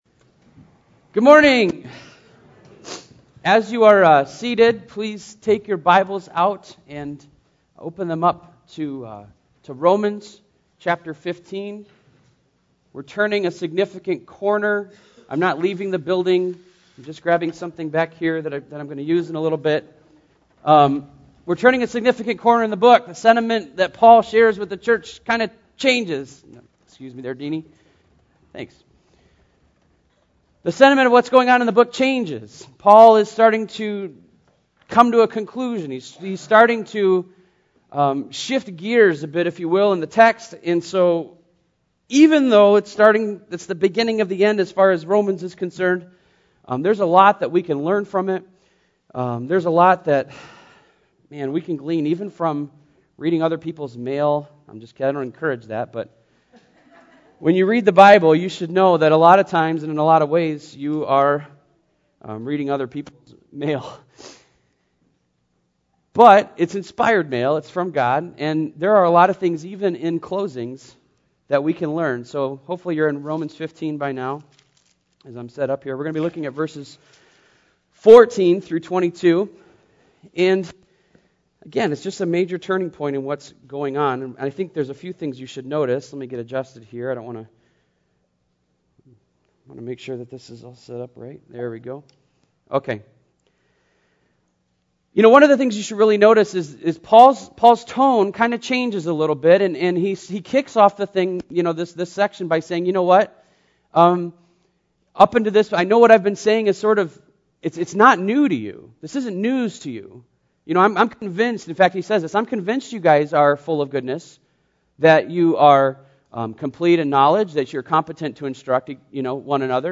sermon-3-4-12.mp3